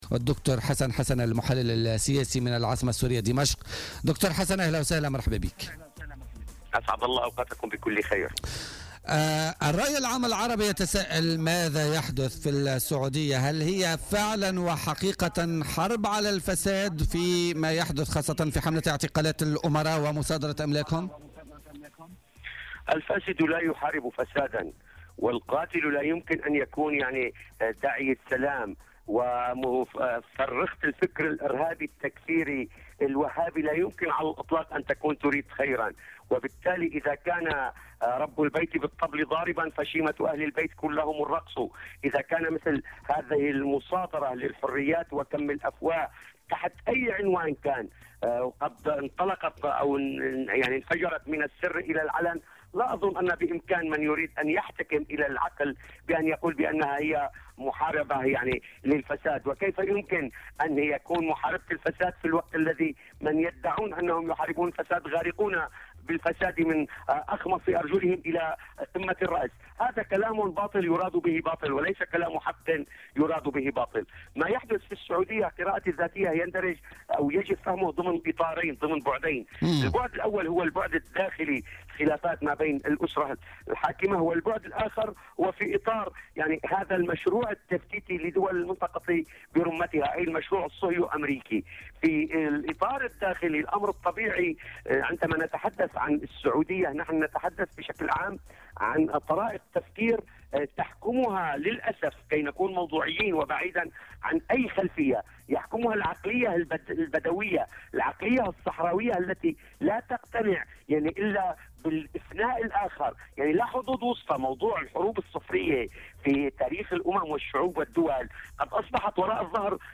محلّل سياسي سوري